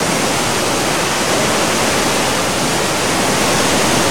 waterfall1.wav